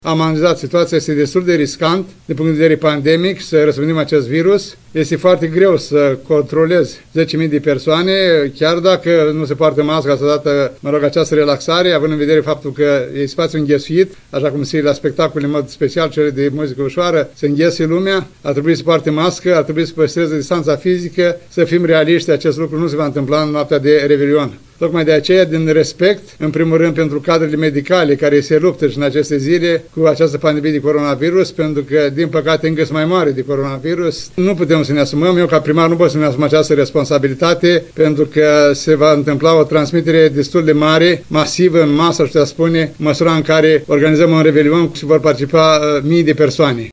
Primarul ION LUNGU a declarat că municipalitatea evită desfășurarea unor activități cu număr mare de oameni “din respect pentru cadrele medicale care luptă cu pandemia”.